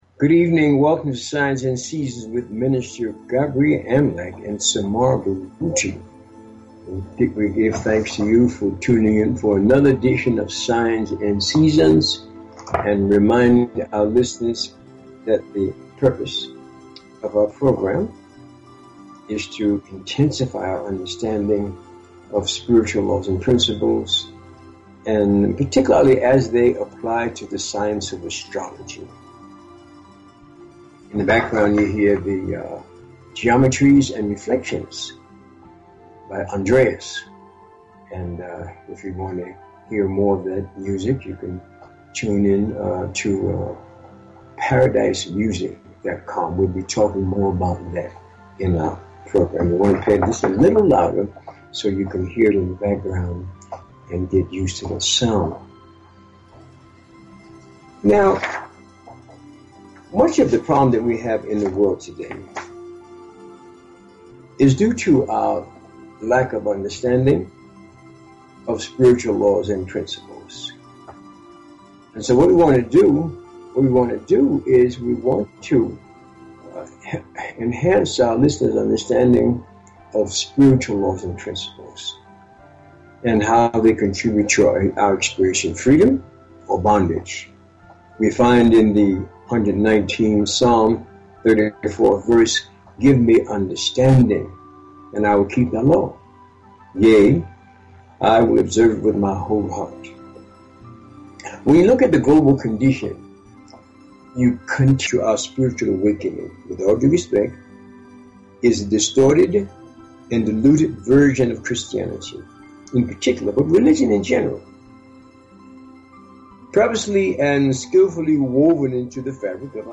Talk Show Episode, Audio Podcast, Signs_and_Seasons and Courtesy of BBS Radio on , show guests , about , categorized as
And to demonstrate the practical value of Astrology and Numerology by giving on air callers FREE mini readings of their personal Astro-numerica energy profiles.